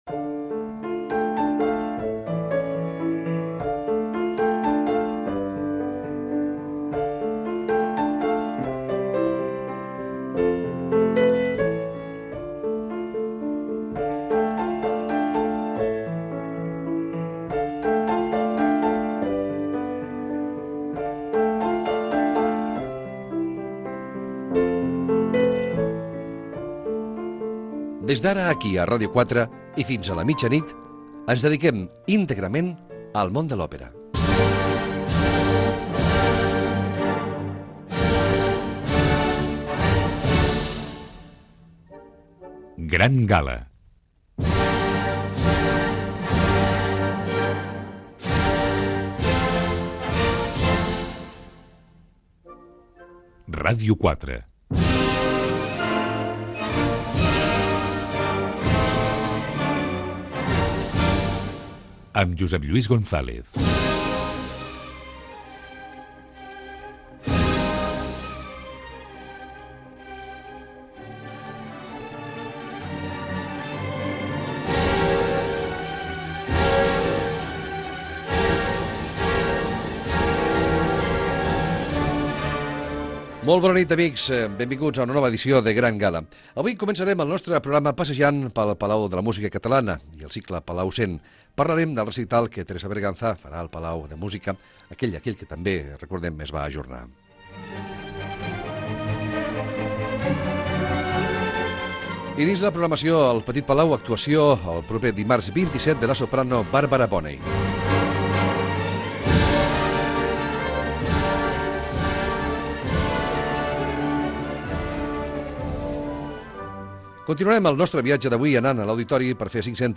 Careta, presentació, sumari del programa, avís que la propera setmana no hi haurà programa, equip
Musical
FM